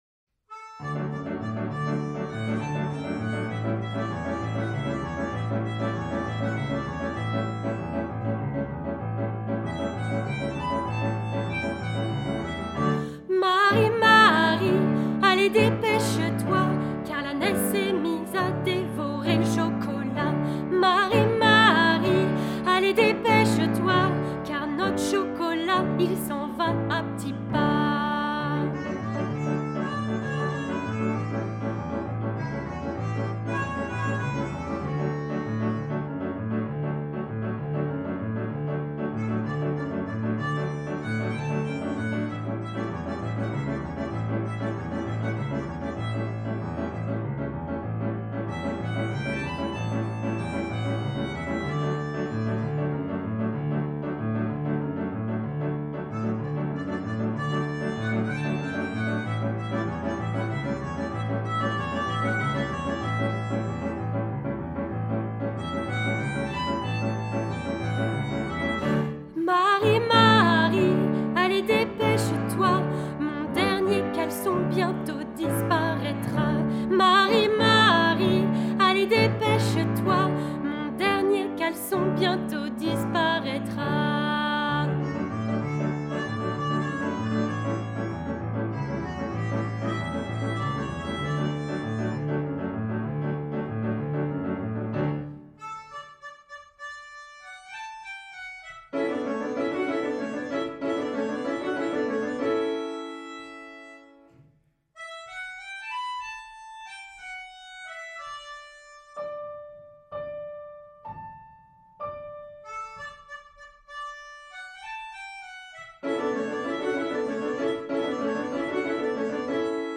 Genre :  ChansonComptine
Style :  Avec accompagnement
Une chanson à l'atmosphère chaleureuse et narrative, parfaite pour la période hivernale !
Enregistrement soprano
Hacia Belelen - Soprano.mp3